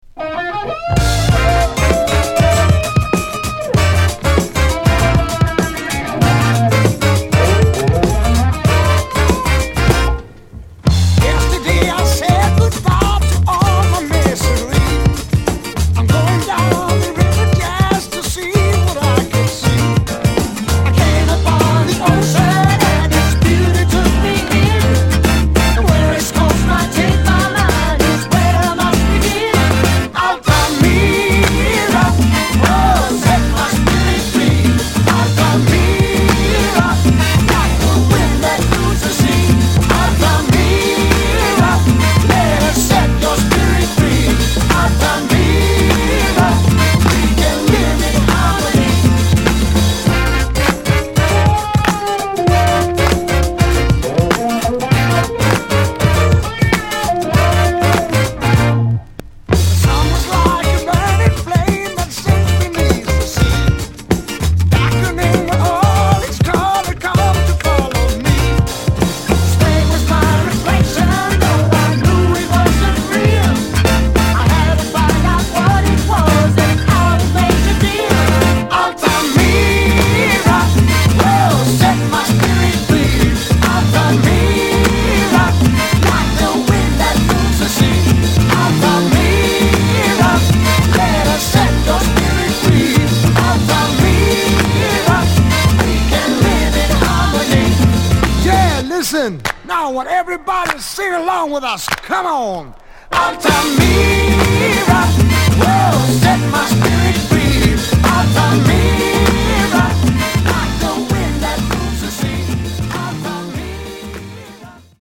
Bossa / Samba sweden
ブラジリアン、ソフトロックなグルーヴィーチューン...